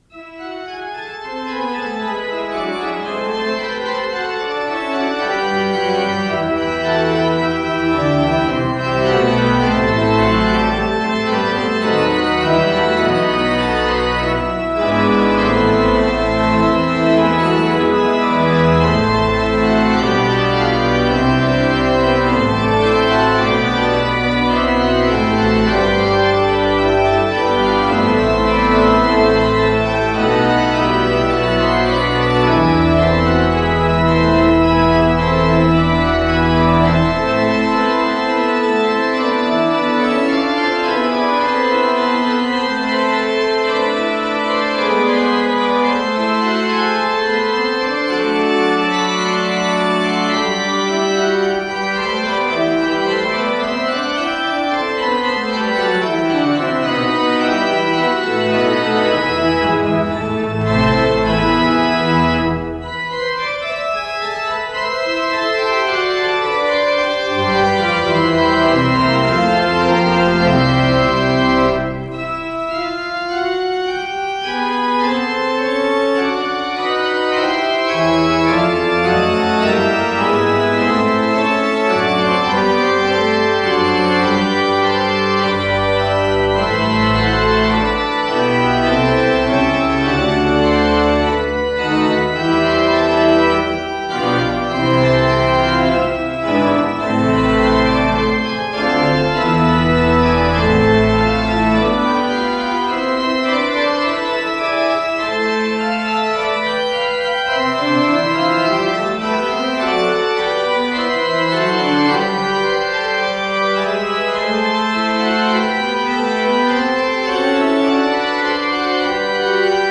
с профессиональной магнитной ленты
ПодзаголовокЛя минор
ВариантДубль моно